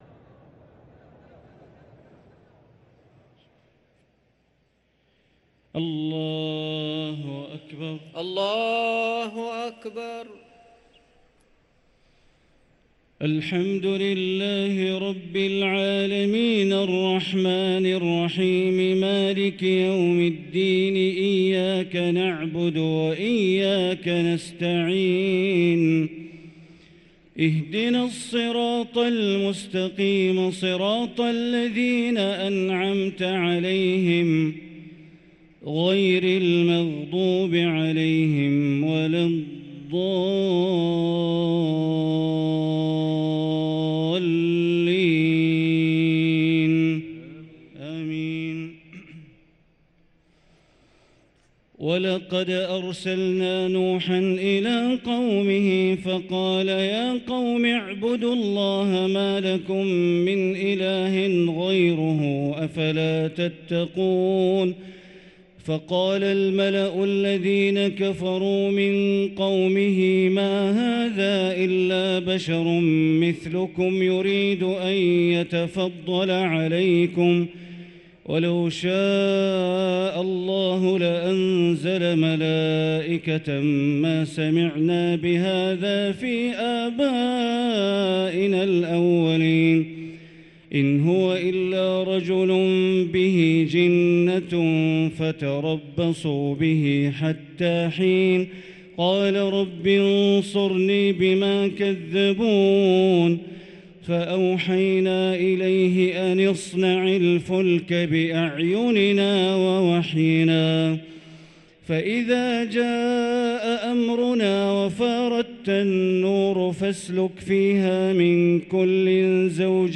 صلاة التراويح ليلة 22 رمضان 1444 للقارئ بندر بليلة - التسليمتان الأخيرتان صلاة التراويح